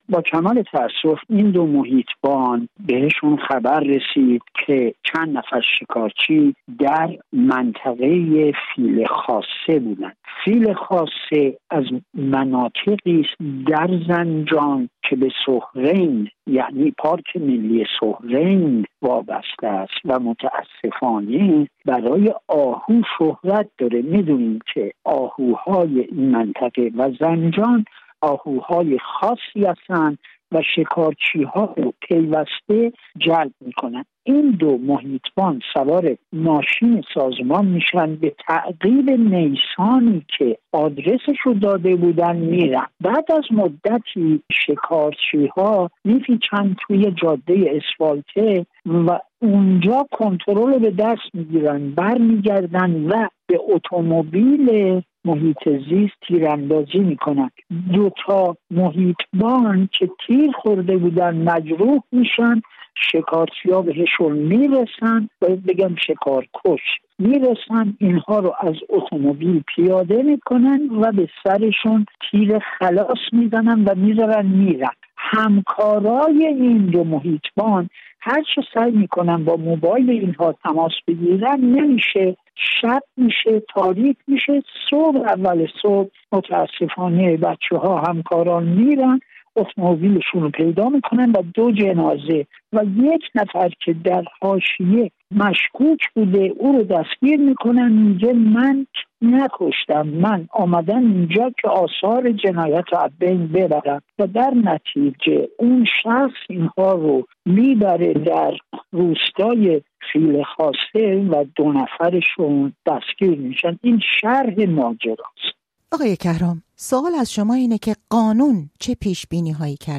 در گفتگو